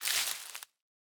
Minecraft Version Minecraft Version latest Latest Release | Latest Snapshot latest / assets / minecraft / sounds / block / leaf_litter / step4.ogg Compare With Compare With Latest Release | Latest Snapshot
step4.ogg